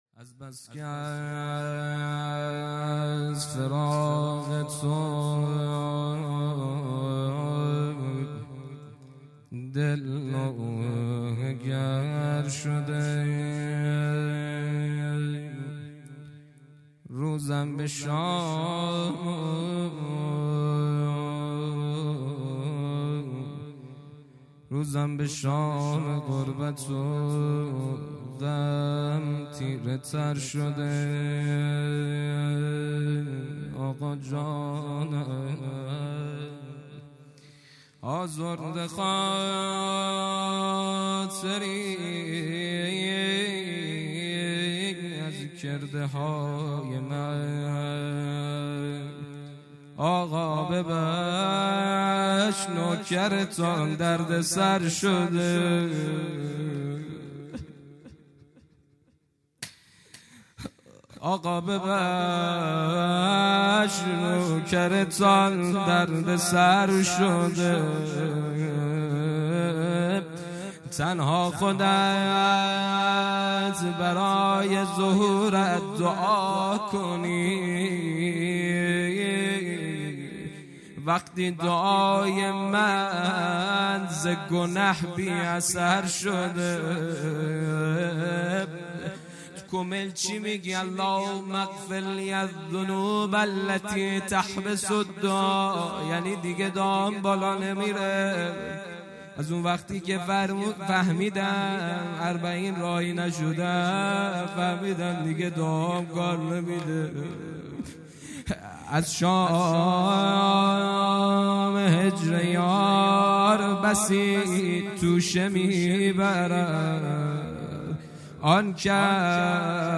روضه
جلسه هفتگی | ۲۲ آبان ۱۳۹۷